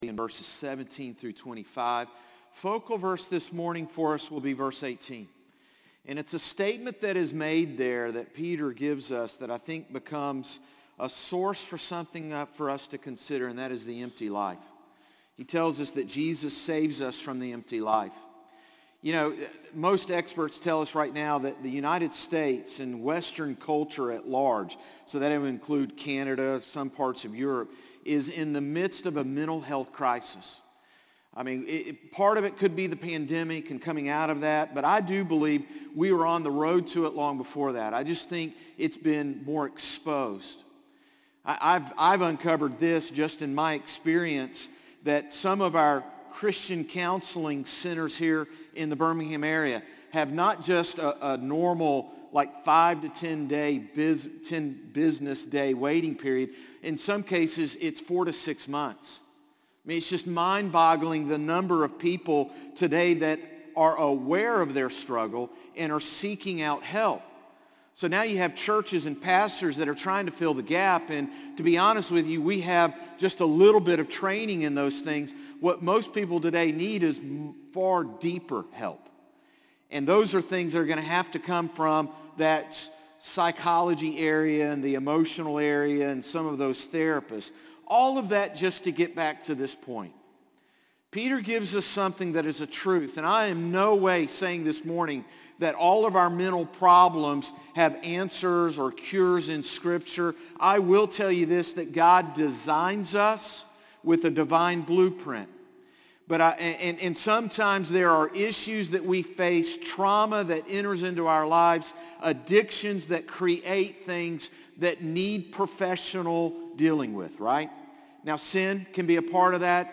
Sermons - Concord Baptist Church
Morning-Service-5-7-23.mp3